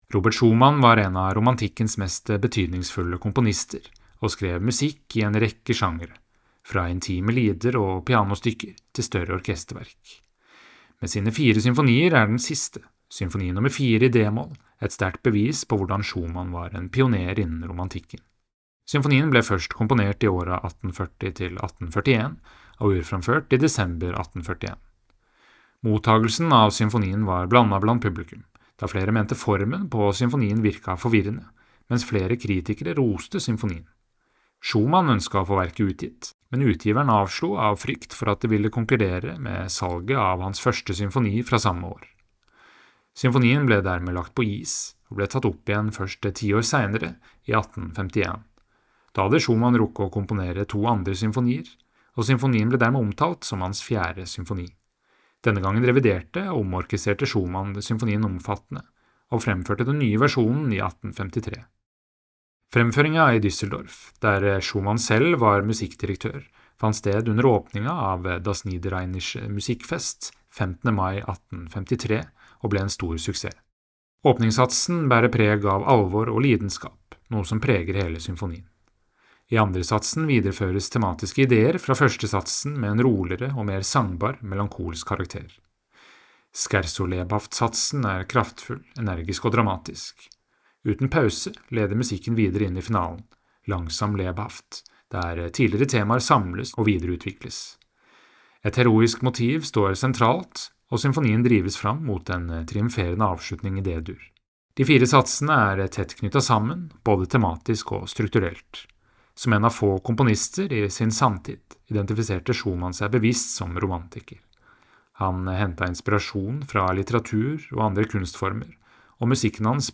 Lytt til verkomtalen VERKOMTALE: Robert Schumanns Symfoni nr. 4